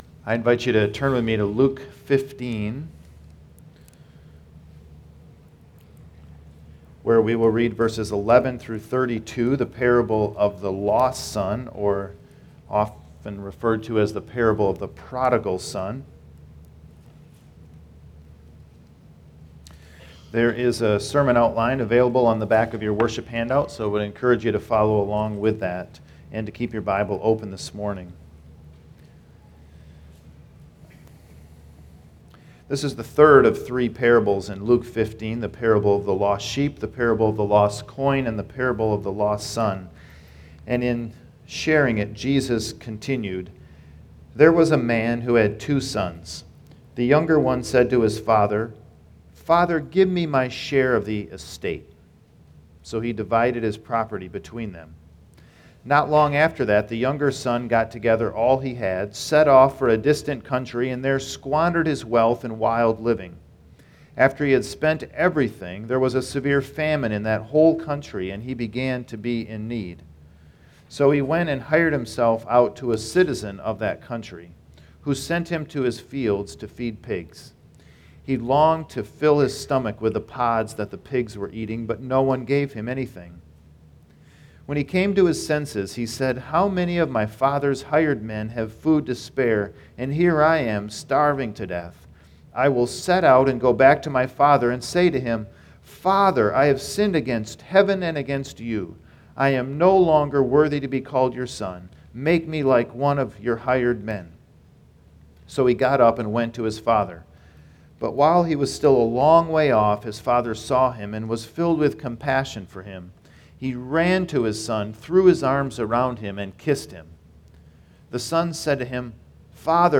Sermons | Woodhaven Reformed Church
woodhavenministries.com_sermon36655.mp3